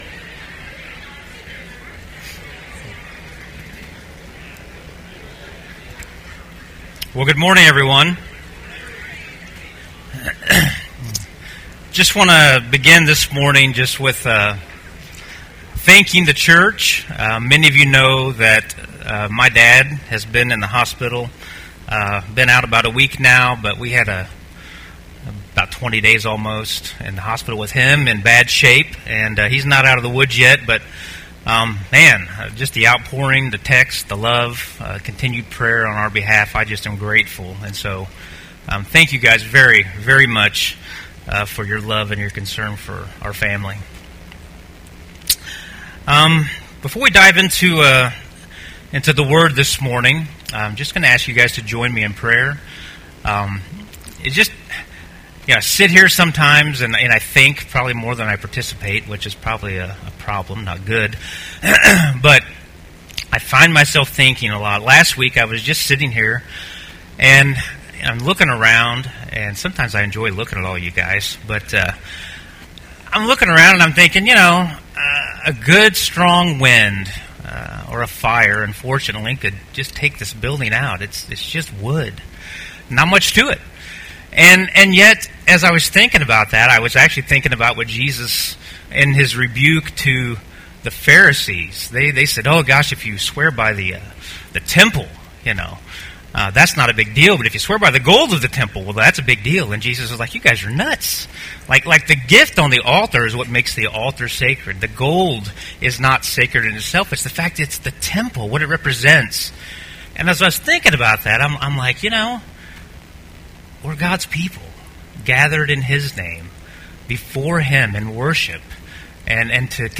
Sermons | Christian Covenant Fellowship